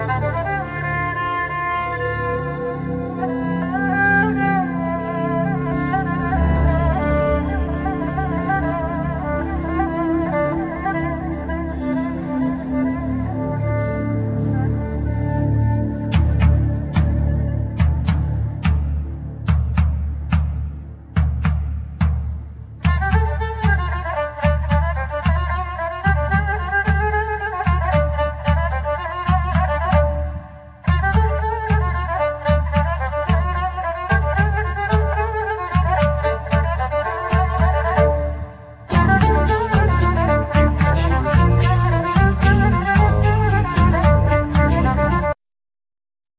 Guitar,Guitar synth
Bass
Percussion
Soprano saxphone,Flute
Lyre